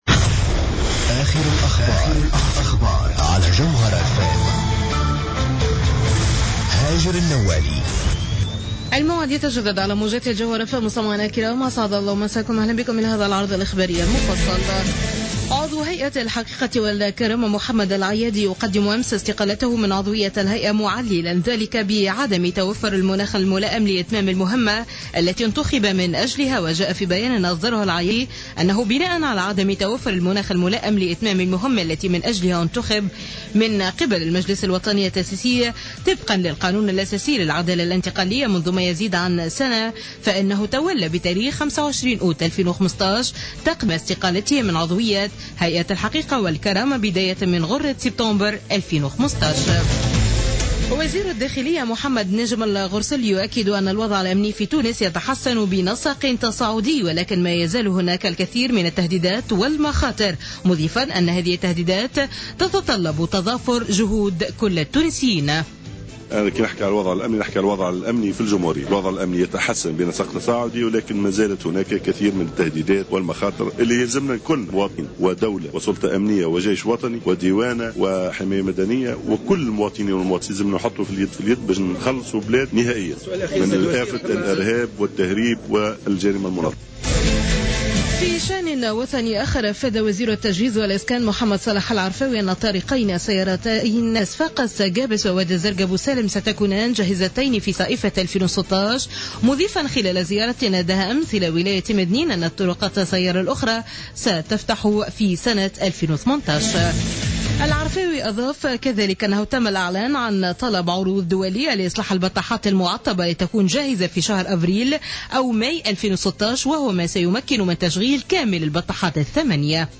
نشرة أخبار منتصف الليل ليوم الأربعاء 26 أوت 2015